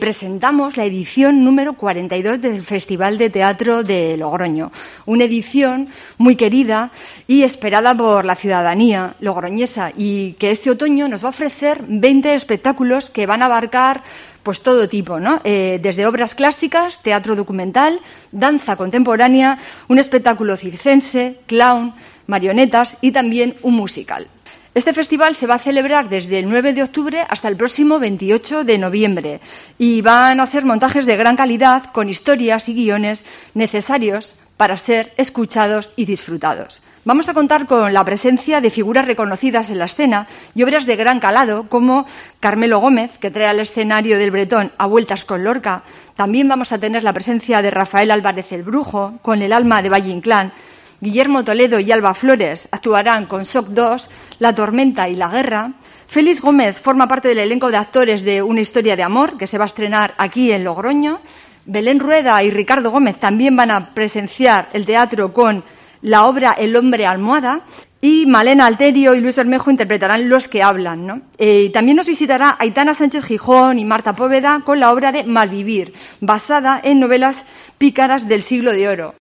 Carmen Urquía, concejala de Cultura de logroño